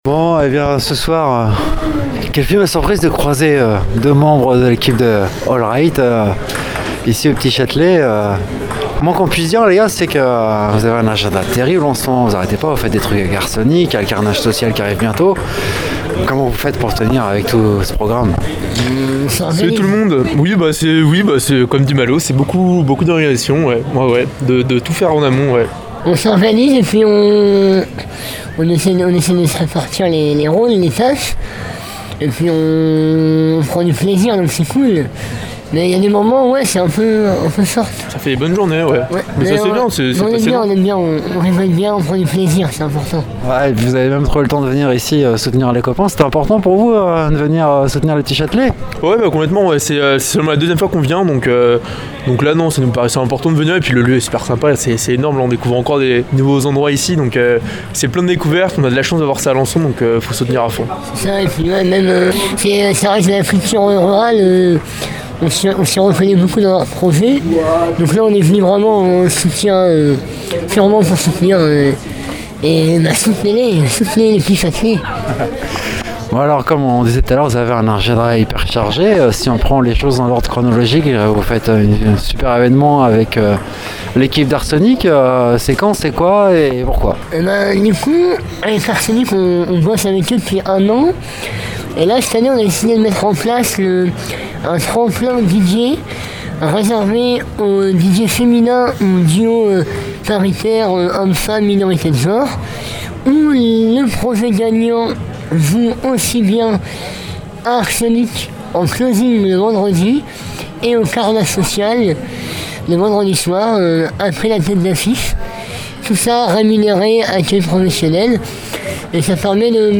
Dans cette interview réalisée aux Petits Châtelets à Alençon lors du Grand Chahut, deux membres du collectif ornais Hole Right présentent leurs projets et leur dynamique collective.